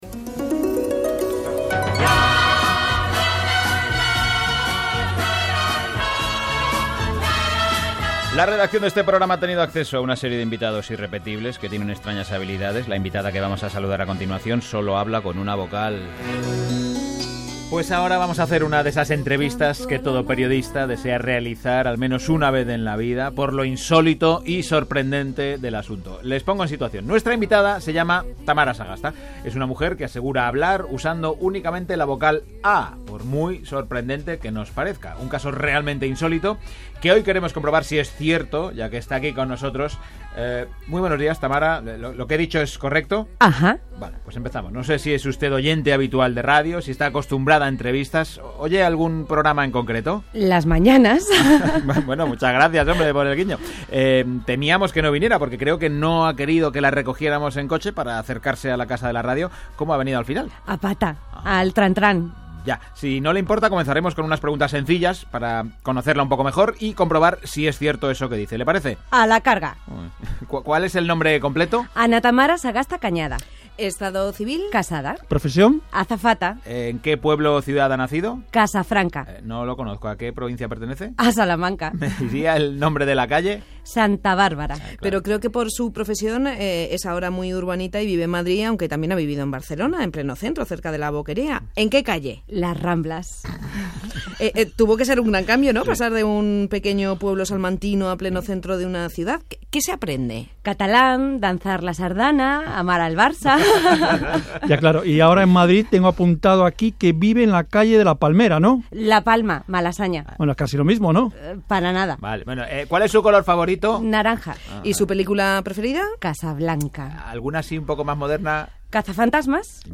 Se trata de una entrevista ficticia a Tamara Sagasta, una mujer que es capaz de hablar empleando solo la vocal A.
Esta mañana lo han emitido en antena y aquí les dejo el corte.